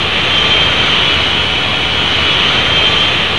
engine_whine.ogg